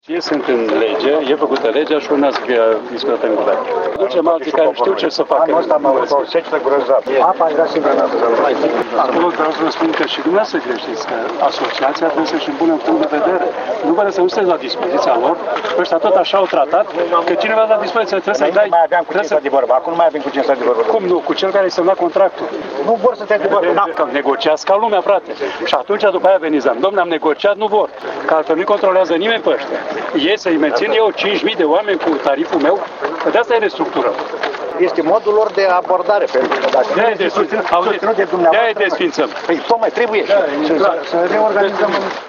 Asaltat de fermieri cu întrebări referitoare la apa de irigat, ministrul agriculturii a confirmat la Orezu proiectul de restructurare a ANIF-ului.
Iar pe fundal se rade, ca doar nu o fi Prefect pe aici!